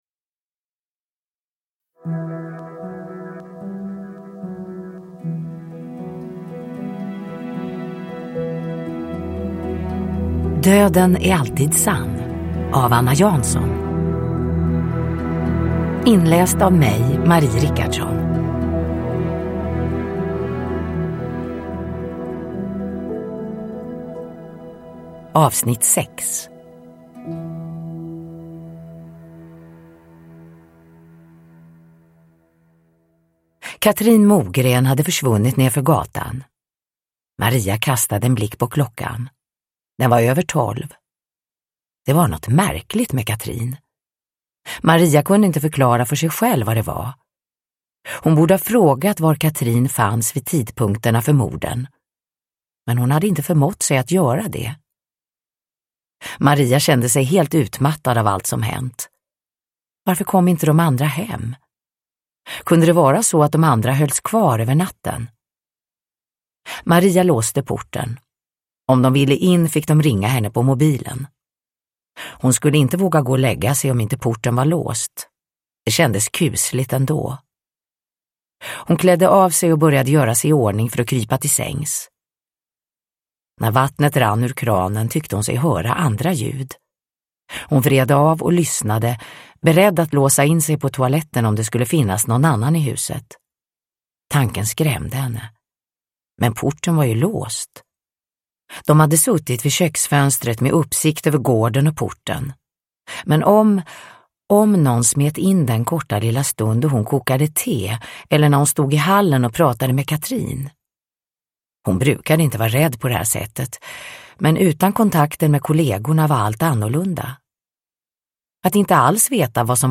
Uppläsare: Marie Richardson